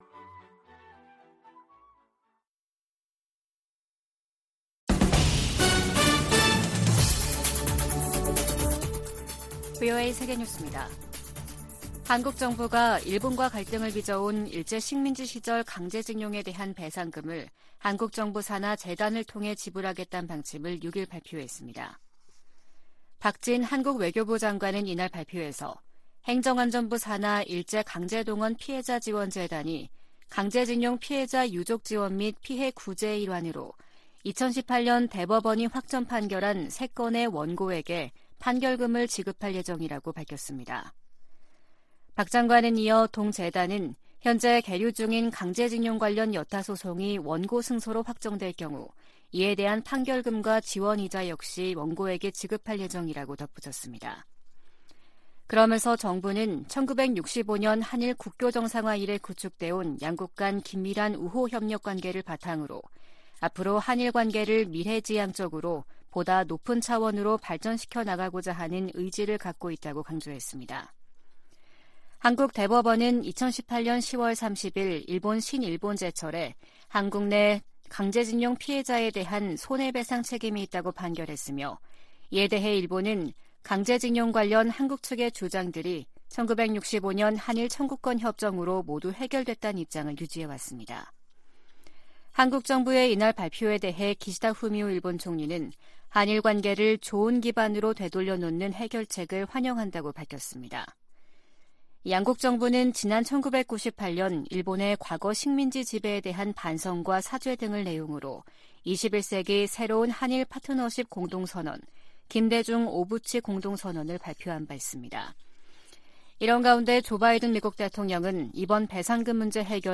VOA 한국어 아침 뉴스 프로그램 '워싱턴 뉴스 광장' 2023년 3월 7일 방송입니다. 한국 정부가 일제 강제징용 해법으로 피해자들에게 국내 재단이 대신 배상금을 지급한다는 결정을 내렸습니다.